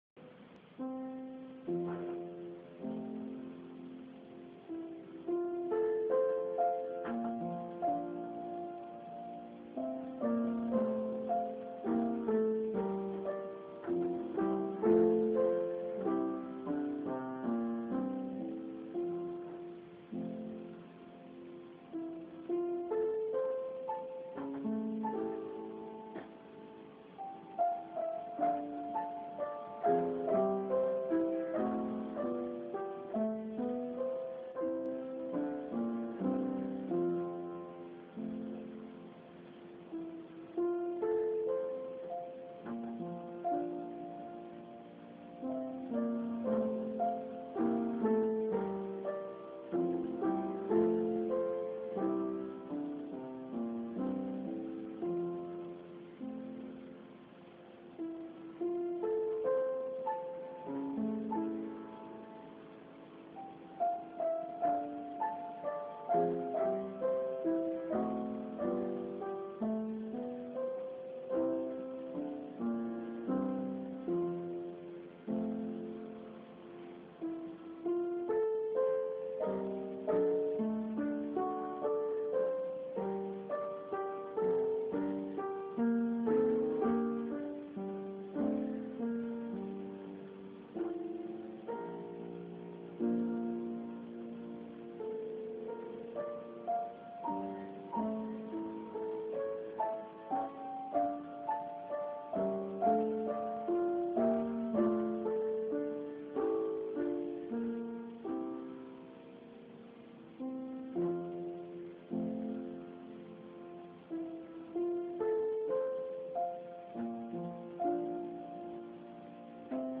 «Грёзы» немецкого композитора XIX века Роберта Шумана (1810–1856) — это седьмая короткая фортепианная пьеса цикла из 13 коротких фортепианных пьес, которые он назвал Kinderszenen, что переводится как «Сцены из детства».
«Грёзы» написаны в фа мажоре, в обычном размере и обозначены как Moderato. Первые четыре такта представляют тему ностальгии: сначала протяжённая нота, затем тоскливо восходящие ноты и плавное нисхождение. Этому отвечают четыре такта, которые направляют тему в несколько ином гармоническом направлении.
Далее следует то, что в поп-музыке называется «бриджем» — контрастный набор тактов, возвращающий к повторению первой темы, и простое, приятное завершение.